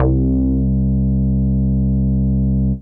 MOOG RESBASS.wav